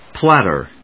/plˈæṭɚ(米国英語), ˈplætɜ:(英国英語)/